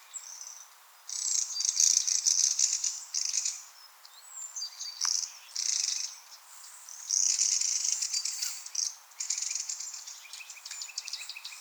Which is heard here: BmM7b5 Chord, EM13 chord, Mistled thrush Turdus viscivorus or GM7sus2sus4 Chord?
Mistled thrush Turdus viscivorus